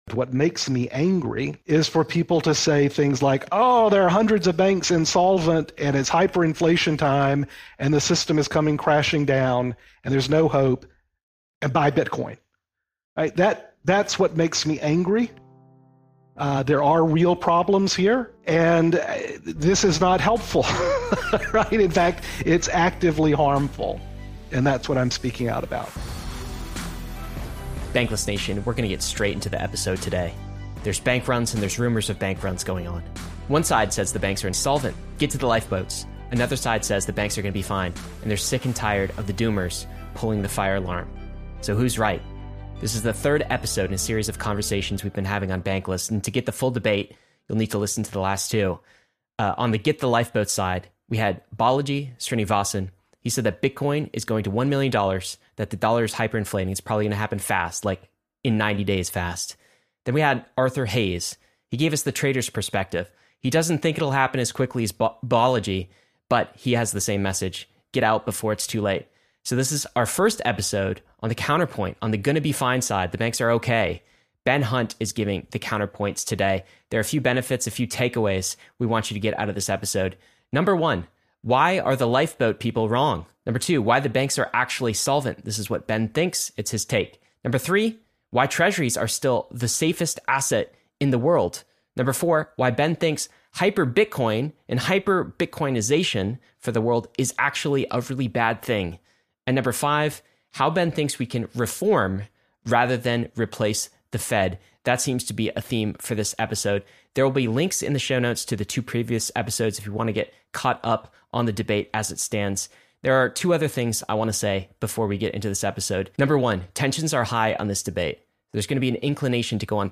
This is our third episode in a series of conversations.